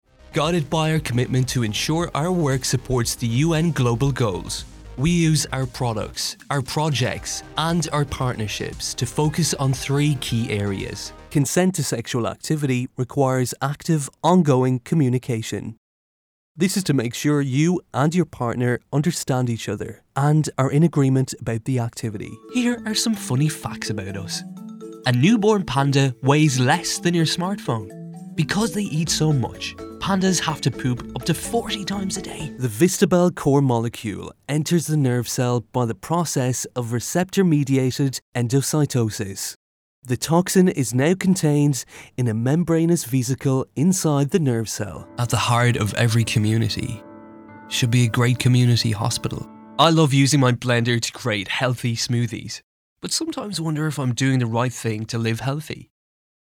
Male
English (Irish)
Yng Adult (18-29), Adult (30-50)
A talented and versatile commercial, narrative & continuity voice.
Corporate
Words that describe my voice are Irish, Smooth, Versatile.